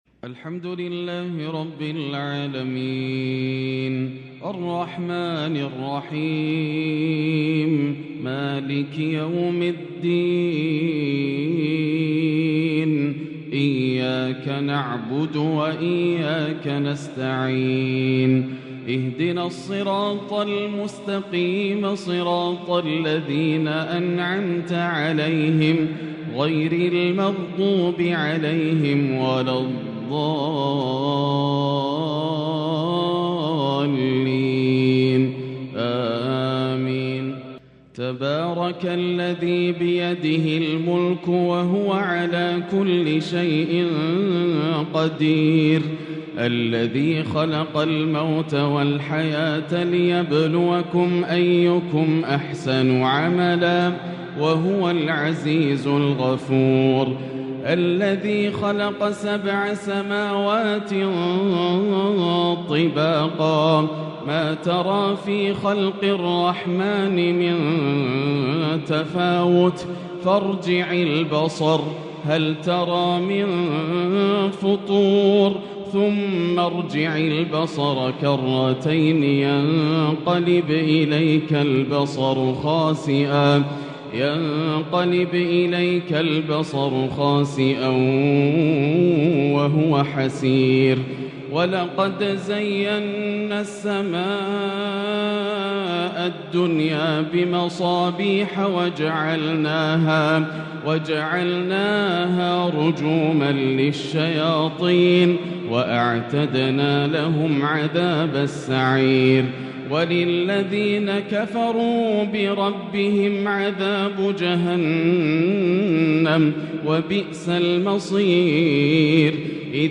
يرتل بعذوبة ويحبر بإبداع أربع سور في أربع فجريات | الشيخ ياسر الدوسري من فجريات شوال وذو القعدة ١٤٤٣ > تلاوات عام 1443هـ > مزامير الفرقان > المزيد - تلاوات الحرمين